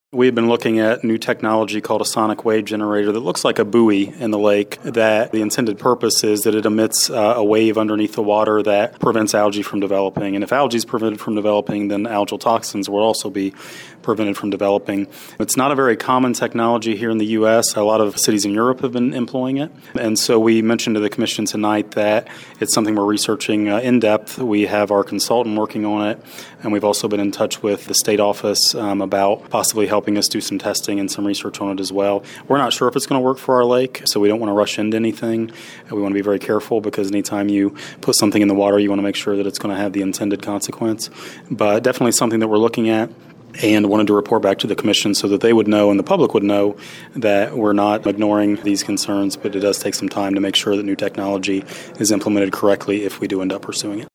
Administrator Burd spoke to WLEN News about the possible solution after the meeting…